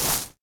default_grass_footstep.3.ogg